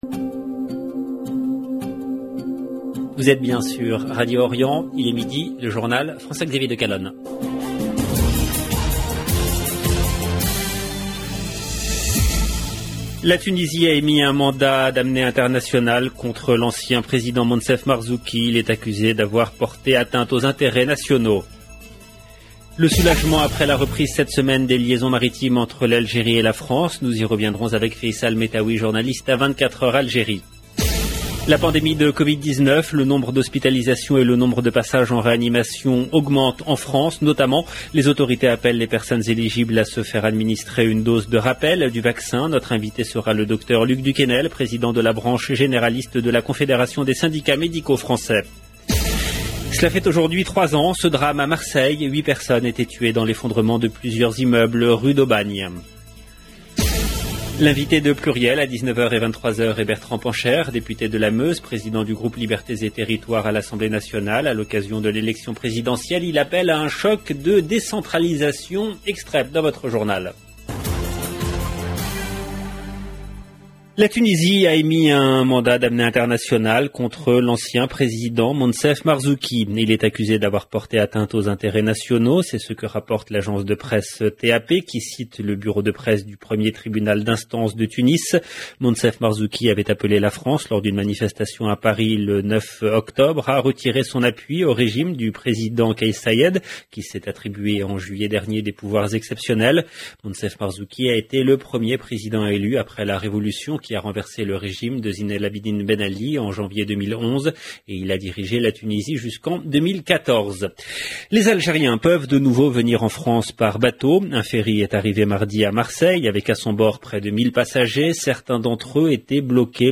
EDITION DU JOURNAL EN LANGUE FRANCAISE DE 12H DU 5/11/2021